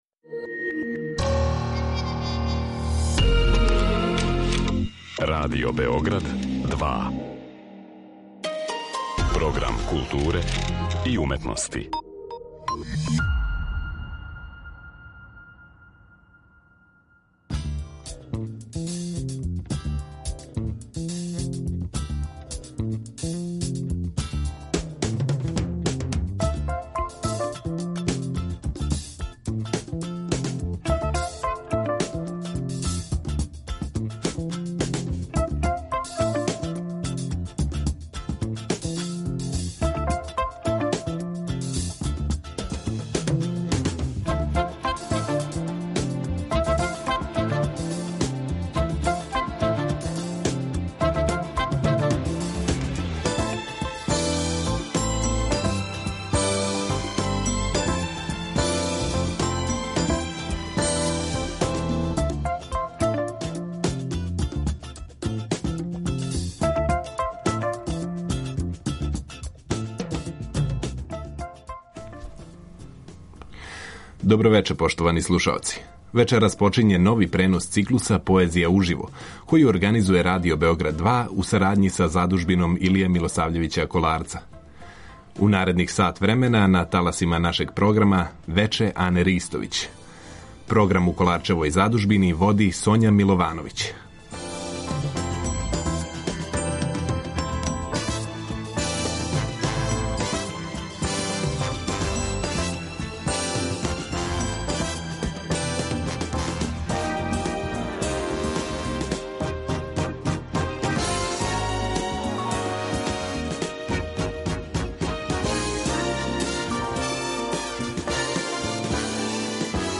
Задужбина Илије Милосављевића Коларца, субота 22. јануар у 19 часова
POEZIJA .mp3